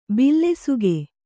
♪ billesuge